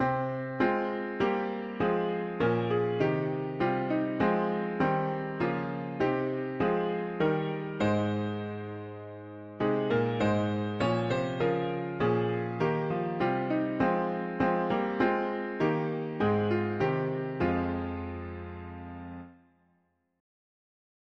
Key: F minor Meter: CM